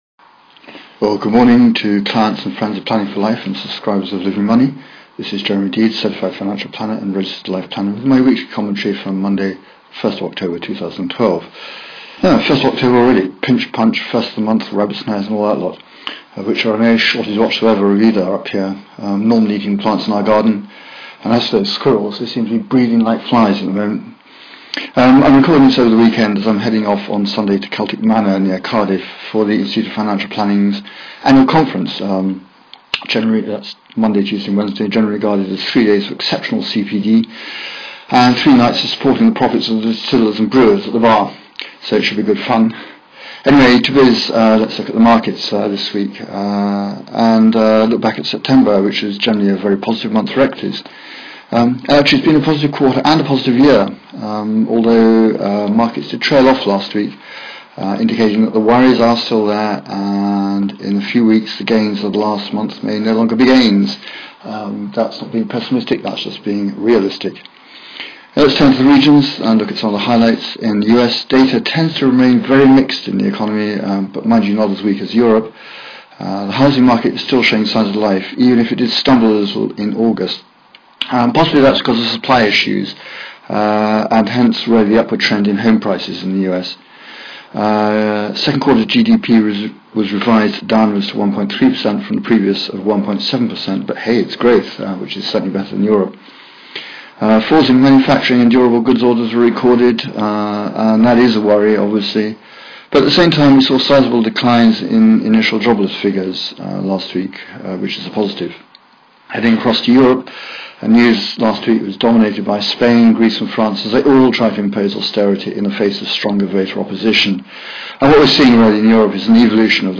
Weekly commentary, 1 October 2012